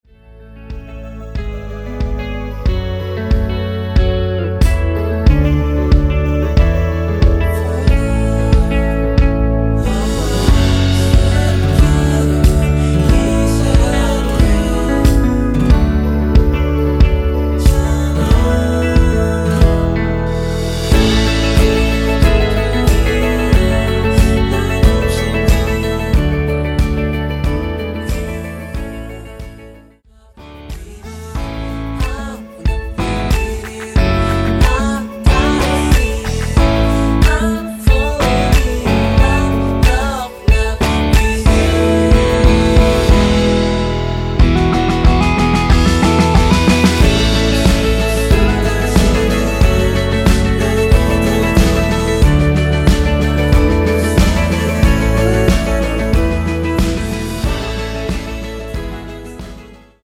원키 멜로디와 코러스 포함된 MR입니다.(미리듣기 확인)
앞부분30초, 뒷부분30초씩 편집해서 올려 드리고 있습니다.